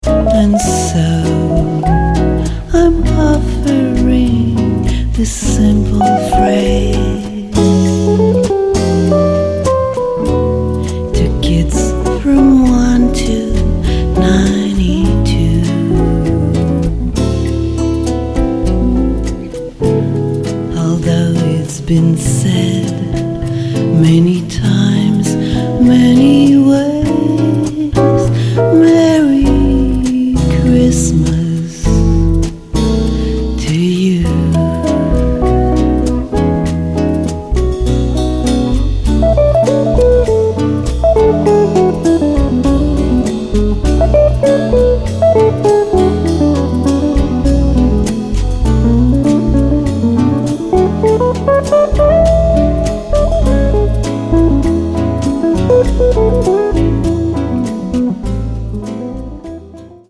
guitare cordes de nylon et percussions
contrebasse
guitare électrique